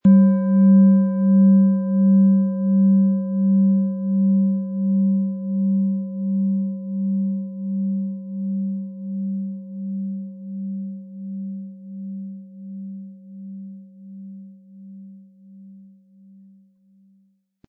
Von Hand getriebene Klangschale mit dem Planetenklang OM-Ton aus einer kleinen traditionellen Manufaktur.
• Tiefster Ton: Biorhythmus Geist
• Höchster Ton: DNA
PlanetentöneOM Ton & Biorhythmus Geist & DNA (Höchster Ton)
MaterialBronze